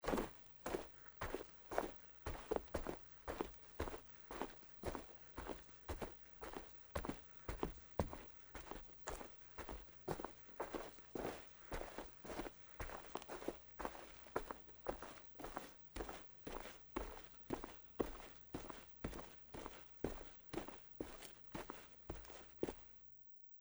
在薄薄的雪地上行走－YS070525.mp3
通用动作/01人物/01移动状态/02雪地/在薄薄的雪地上行走－YS070525.mp3
• 声道 立體聲 (2ch)